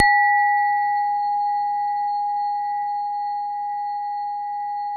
WHINE  G#3-L.wav